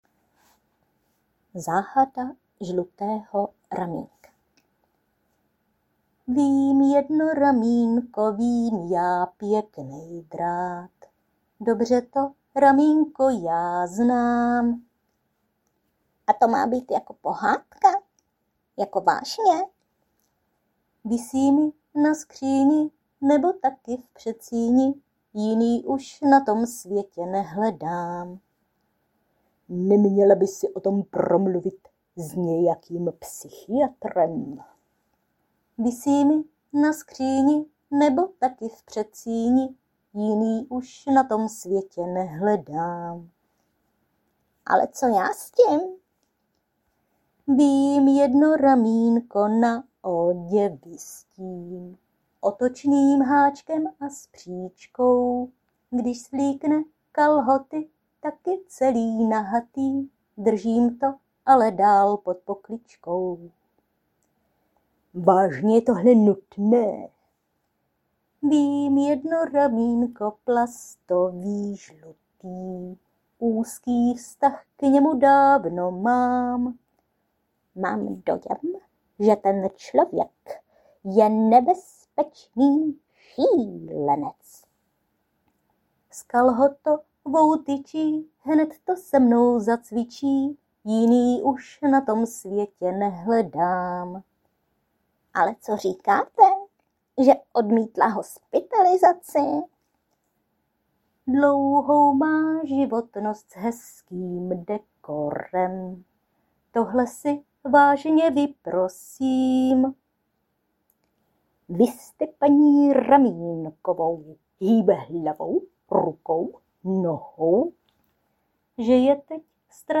Anotace: hlasová improvizace